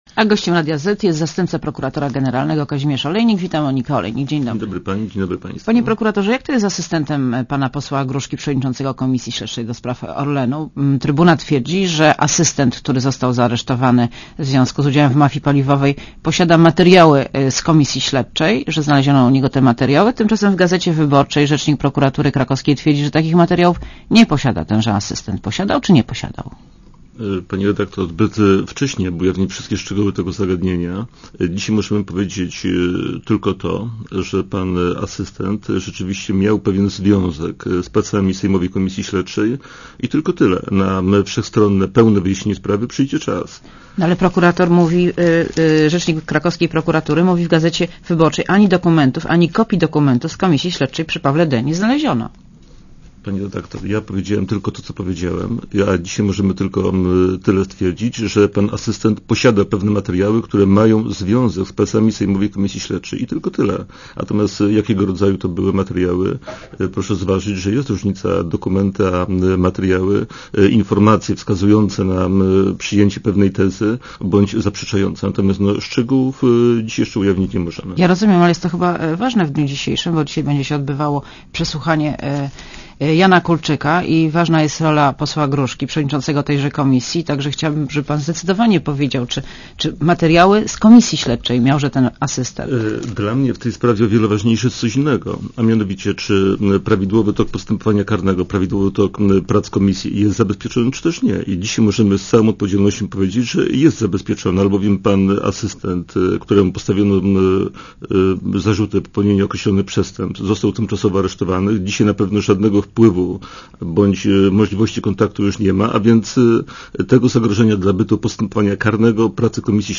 Posłuchaj wywiadu Gościem Radia Zet jest zastępca prokuratora generalnego, Kazimierz Olejnik.